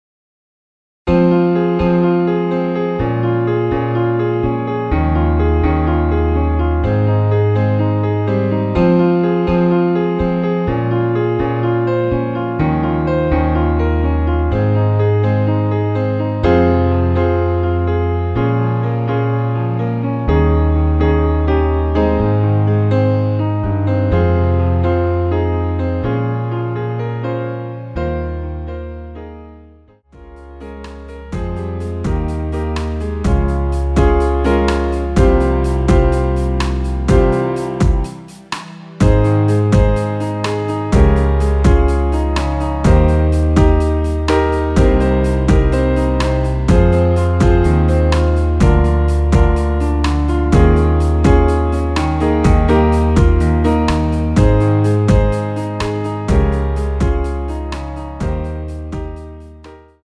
Fm
앞부분30초, 뒷부분30초씩 편집해서 올려 드리고 있습니다.